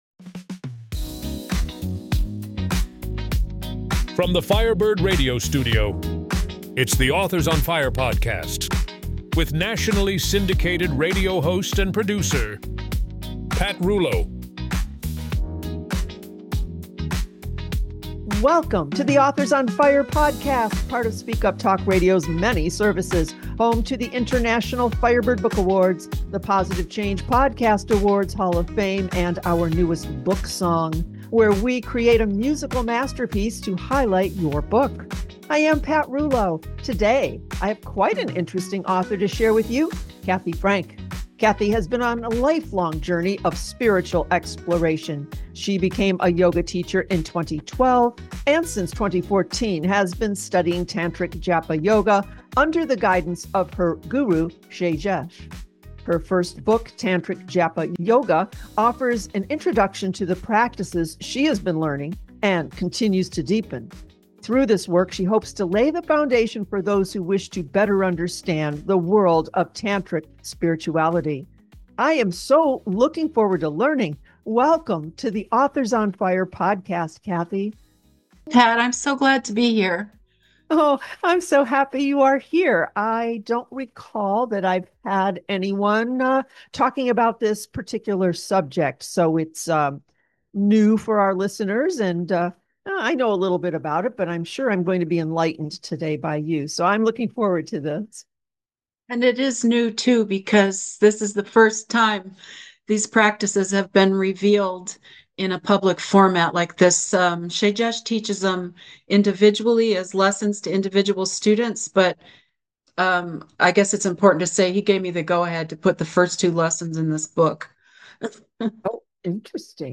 Authors on Fire Interview